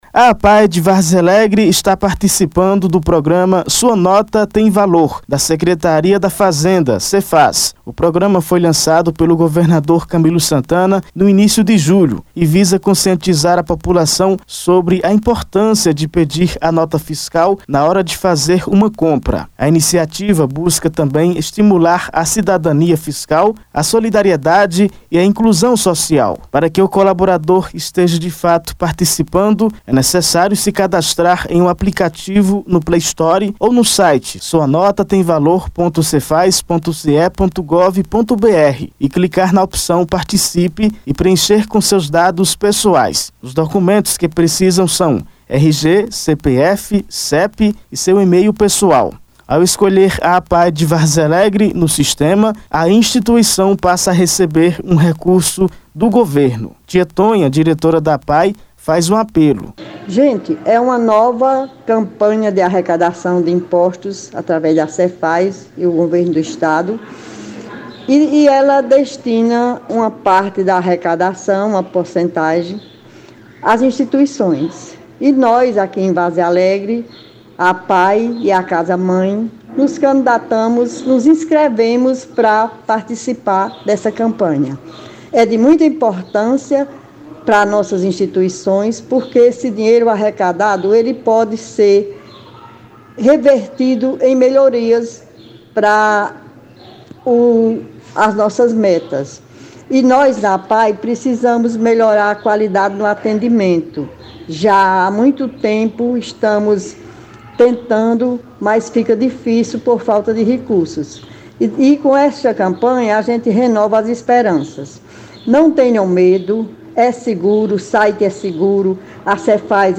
A APAE de Várzea Alegre está participando do programa sua nota tem valor da Secretaria da Fazenda (SEFAZ/CE). Acompanhe a reportagem completa: